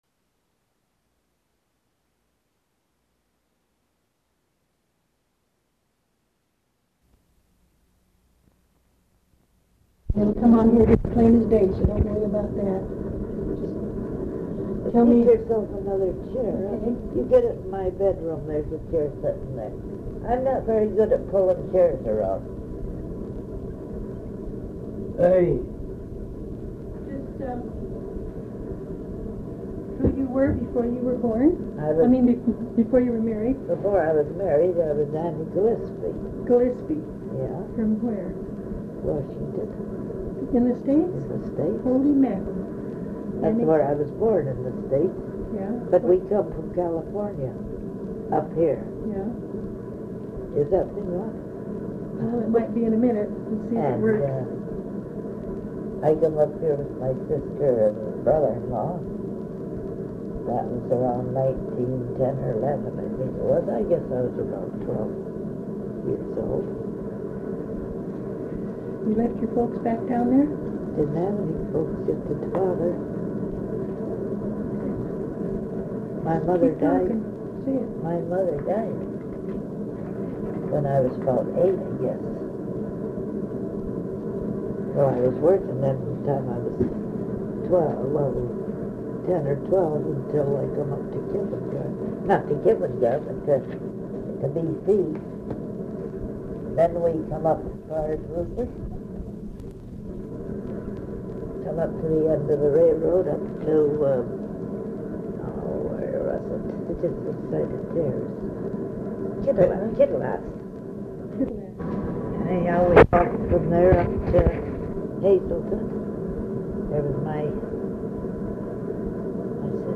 Interview as part of the Hearing Hazelton History project, managed by the Hazelton Area Historical Association.,